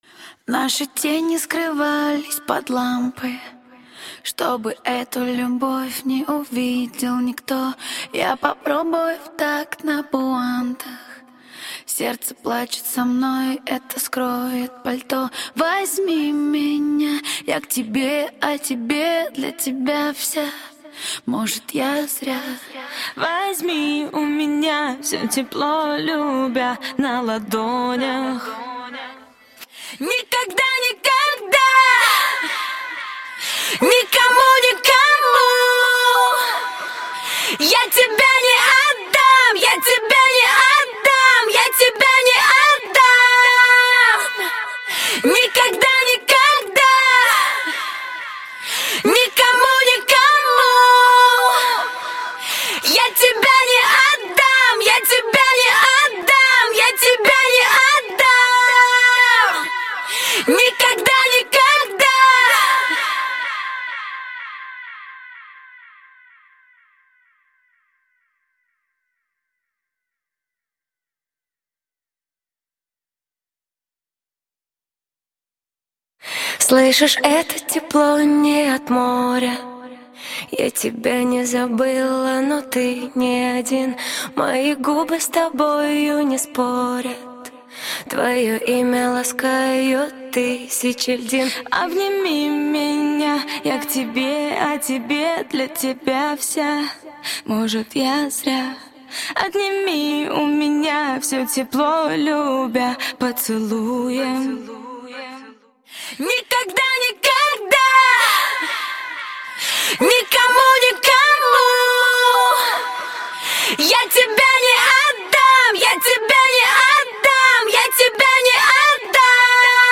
Главная » Файлы » Акапеллы » Скачать Русские акапеллы